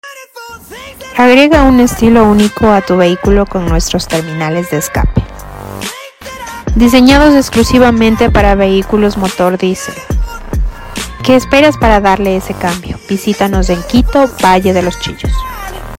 📍Vehículos motor Diésel terminales de